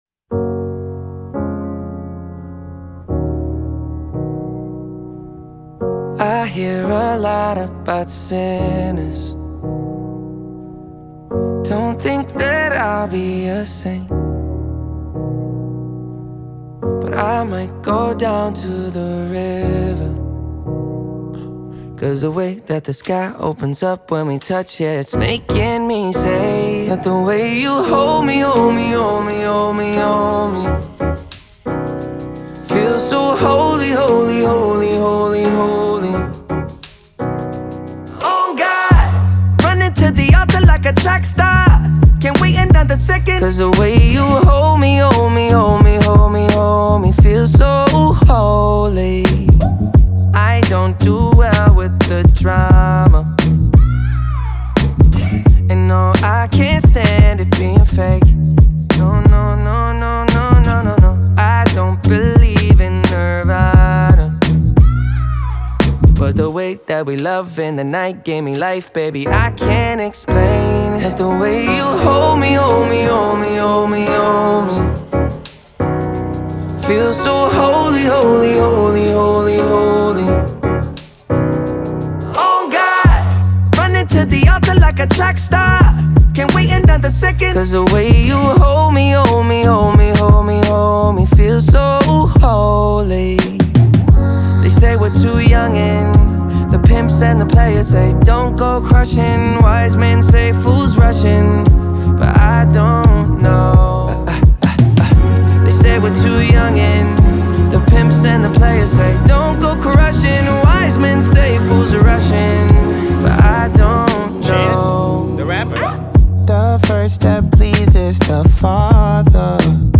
It is a pop and R&B song with elements of gospel.